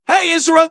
OpenVoiceOS/synthetic-wakewords at 300aefb07439dd810ff77155c2e035ab27ad401b
ovos-tts-plugin-deepponies_Scout_en.wav